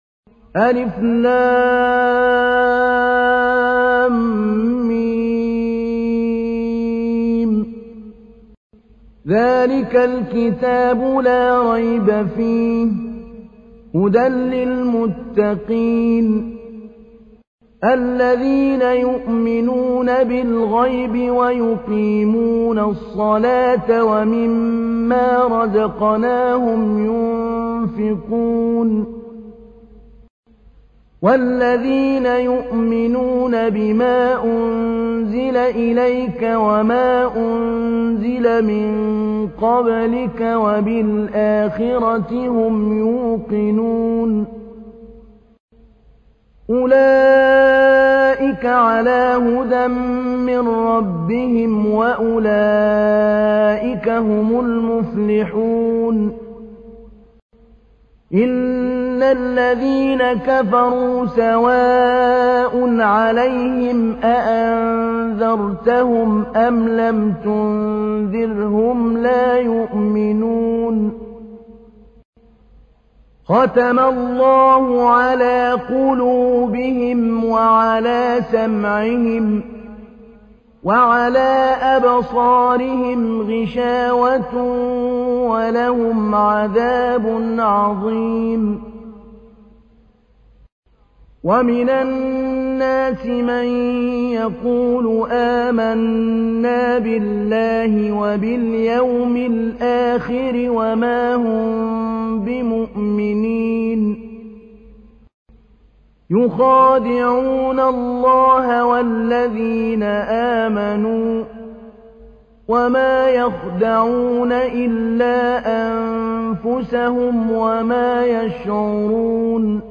تحميل : 2. سورة البقرة / القارئ محمود علي البنا / القرآن الكريم / موقع يا حسين